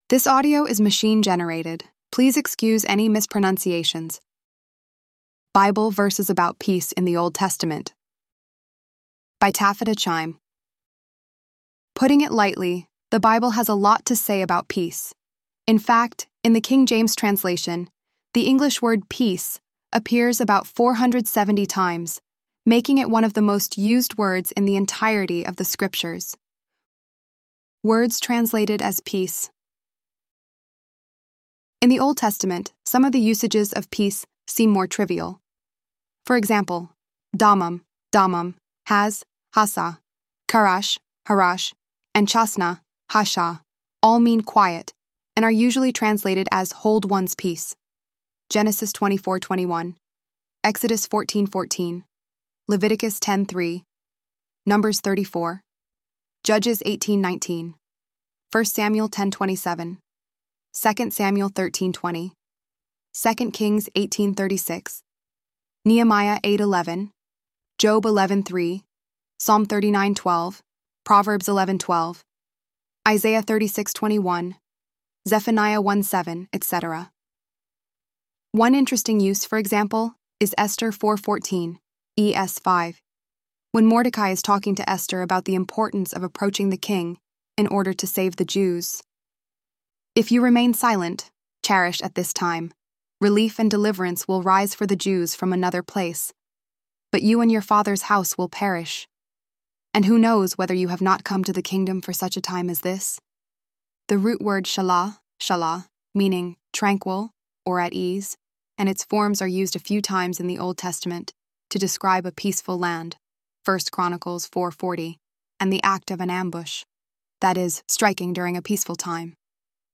ElevenLabs_1_15.mp3